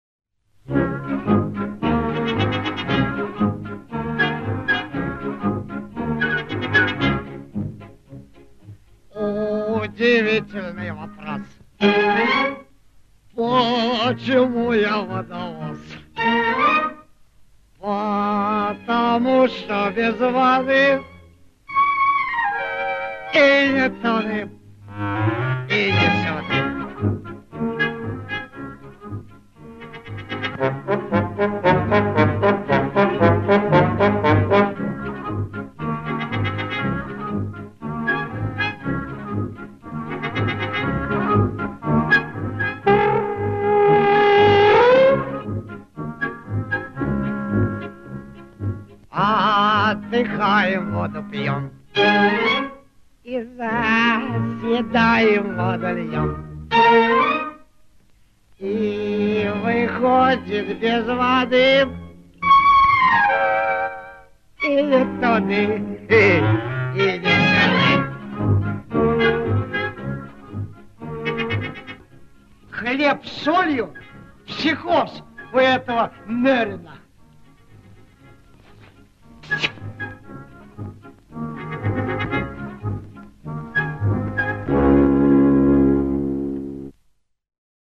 Звуковые дорожки песен из старых фильмов.